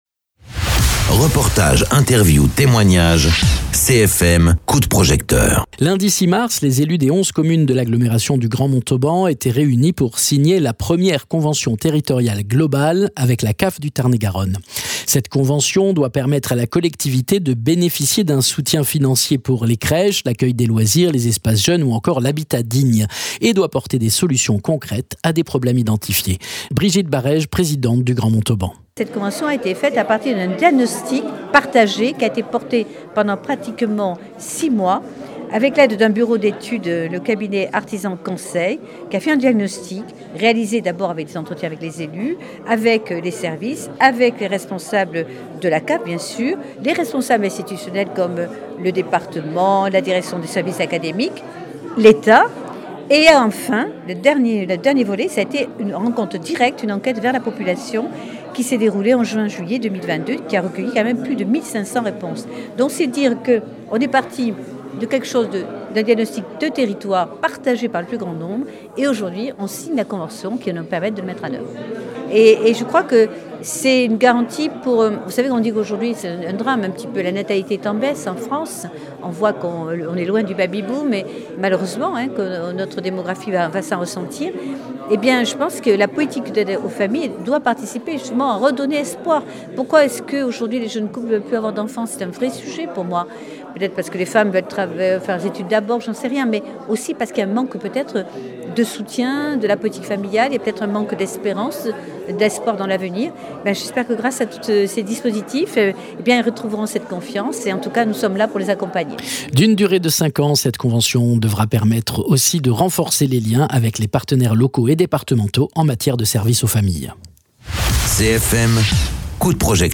Interviews
Invité(s) : Brigitte Barèges présidente du Grand Montauban